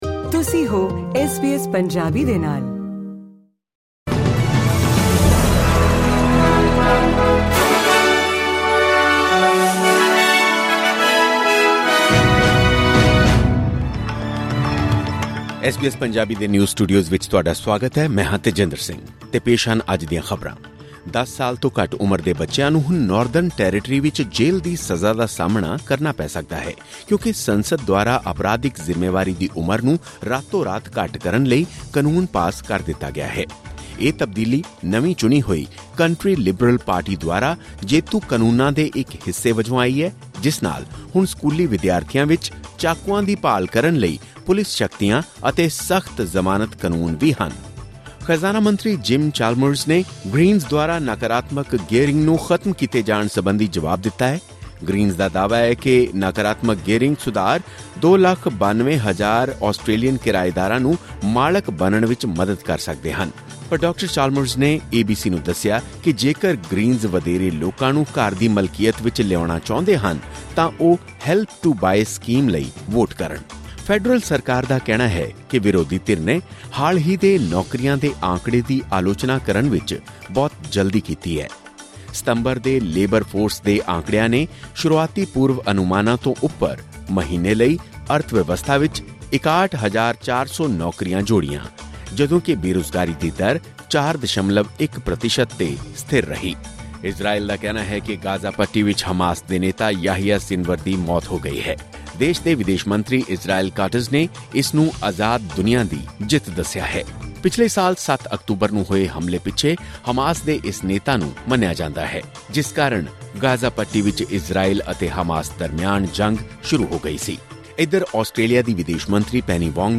ਐਸ ਬੀ ਐਸ ਪੰਜਾਬੀ ਤੋਂ ਆਸਟ੍ਰੇਲੀਆ ਦੀਆਂ ਮੁੱਖ ਖ਼ਬਰਾਂ: 18 ਅਕਤੂਬਰ, 2024